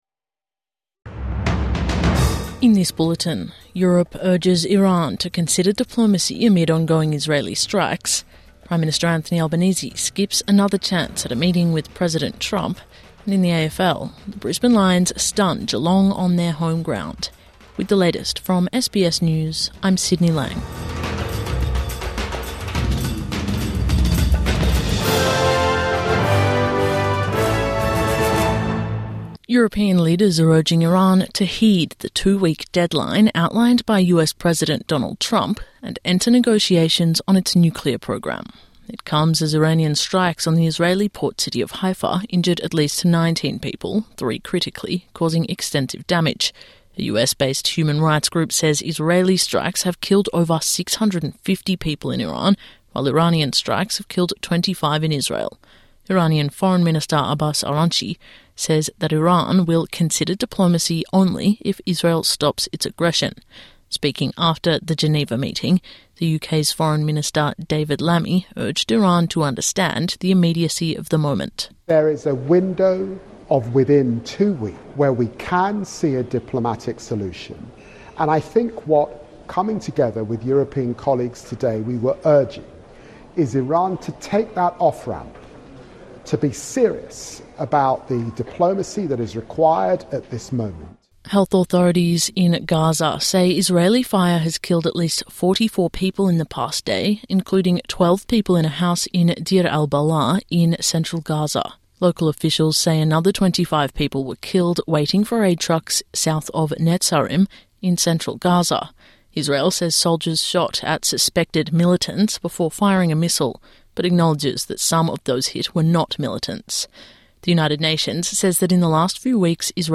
Midday News Bulletin 21 June 2025